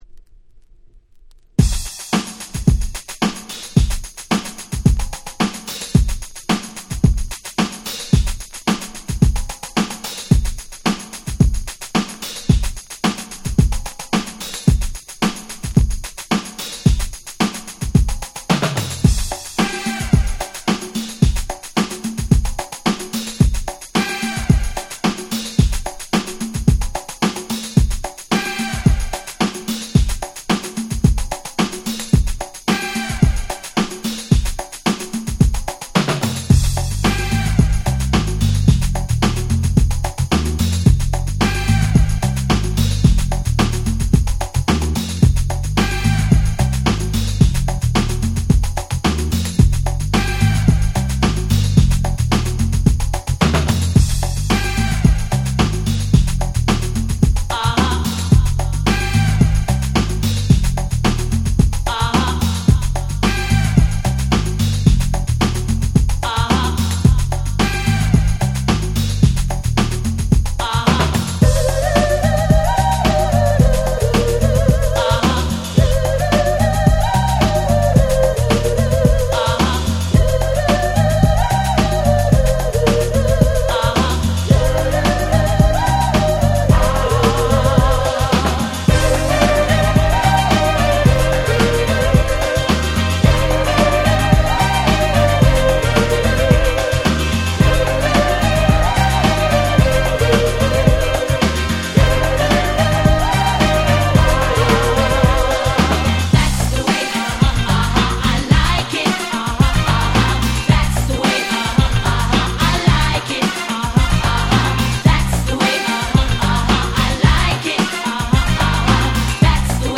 98’ Super Nice Cover !!
Disco Dance Pop